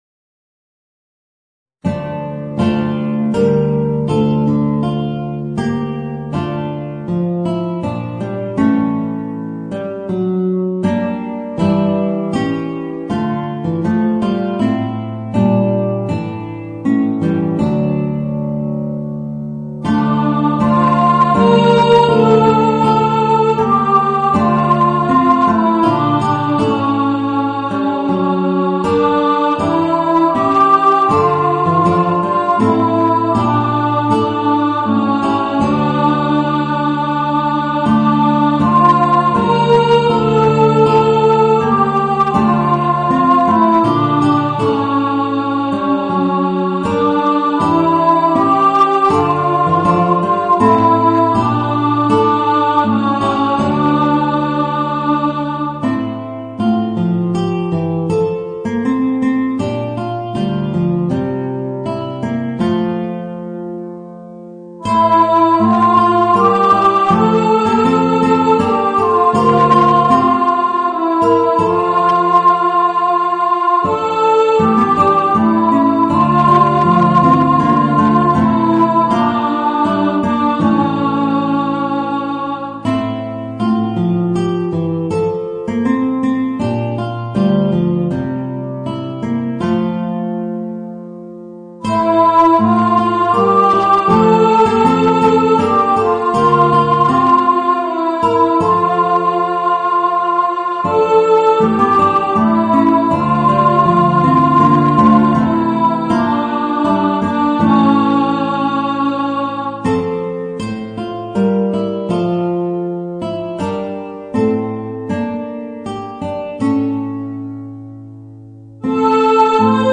Voicing: Guitar and Alto